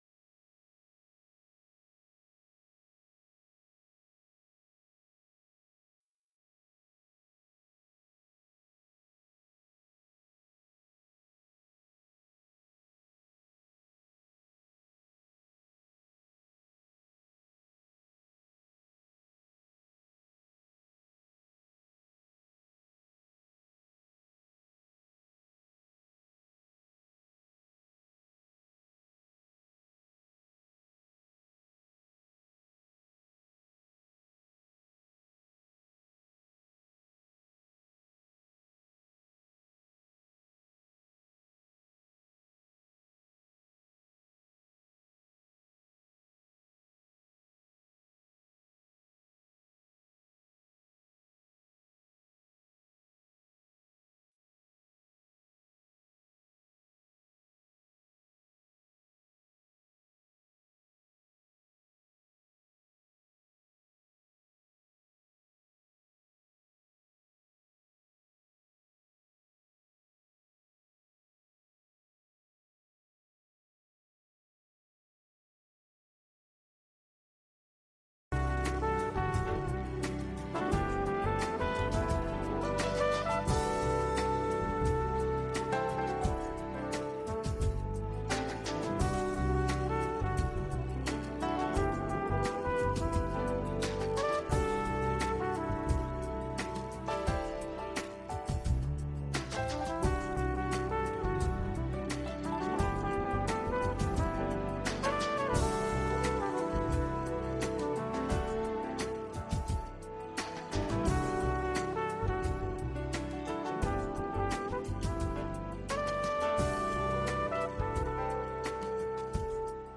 VOA 한국어 '출발 뉴스 쇼', 2023년 1월 4일 방송입니다. 유럽연합과 영국, 캐나다 등이 북한에 군사적 긴장 고조행위를 중단하고 비핵화 대화에 복귀하라고 촉구했습니다. 조 바이든 미국 대통령에게는 북한 핵 문제를 비롯한 세계적인 핵무기 위험이 새해에도 주요 외교적 도전이 될 것이라고 미국 외교전문지가 지적했습니다.